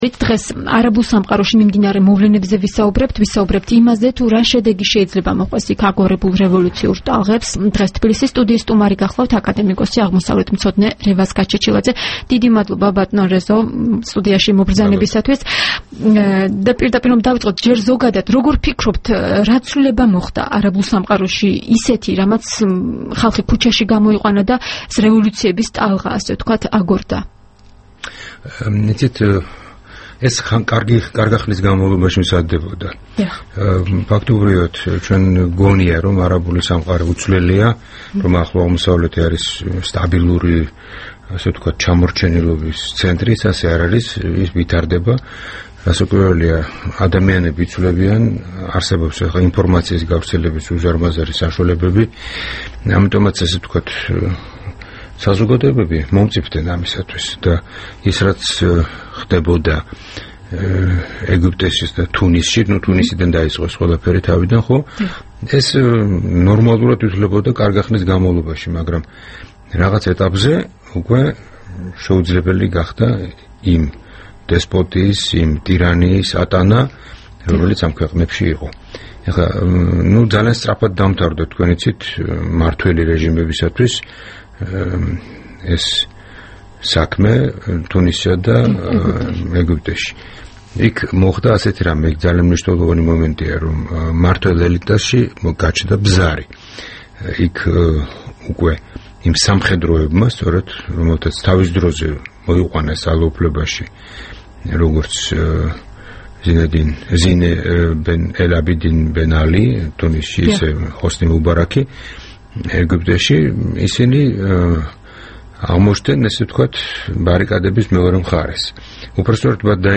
26 აგვისტოს, თბილისის სტუდიის სტუმარი იყო აკადემიკოსი, აღმოსავლეთმცოდნე რევაზ გაჩეჩილაძე.
საუბარი რევაზ გაჩეჩილაძესთან